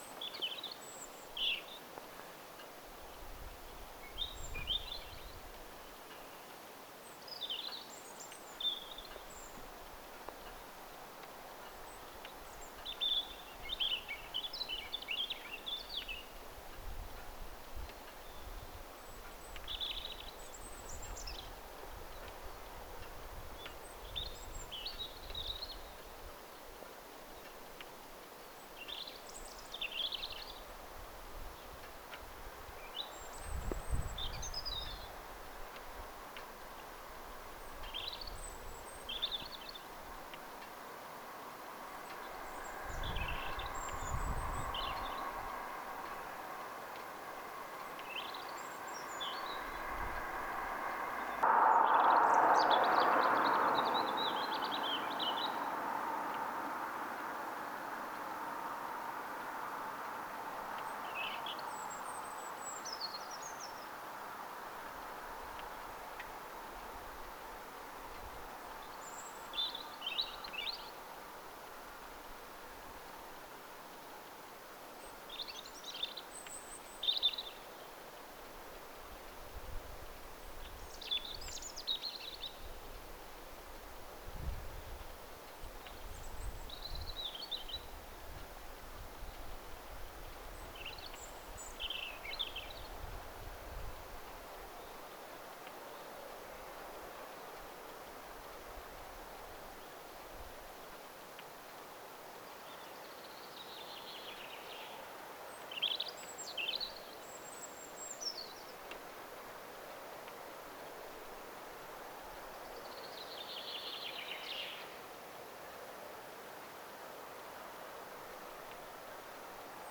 Tässä on neljä tai viisi laulavaa punarintaa.
1nen_punarinta_laulaa.mp3